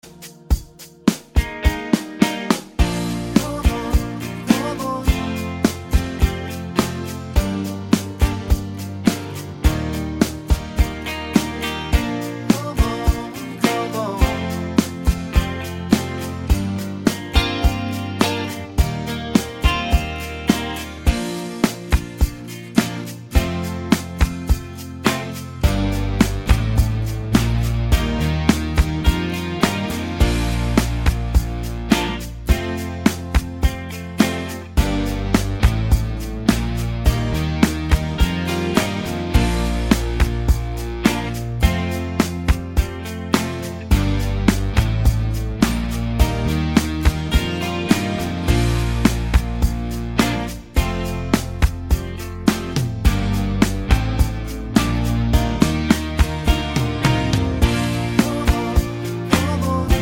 no Backing Vocals Soundtracks 4:16 Buy £1.50